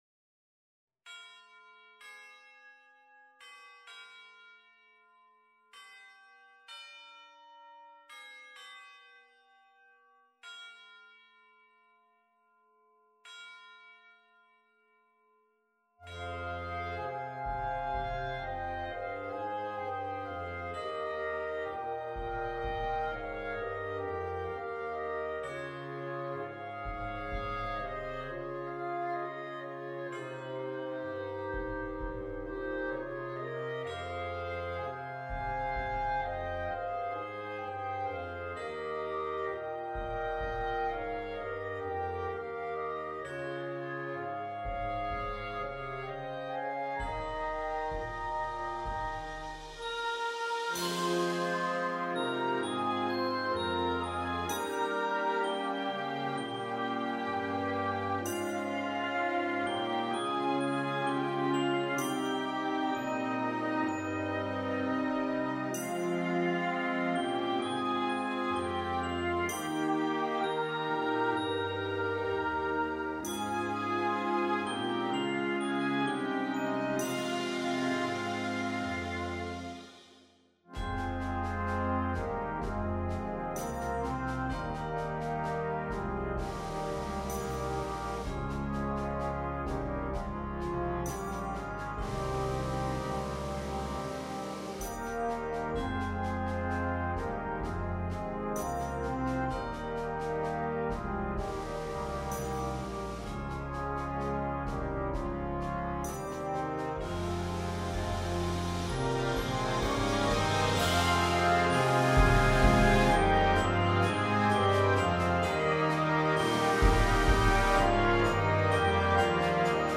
A very solemn piece. Very playable with some lovely lines.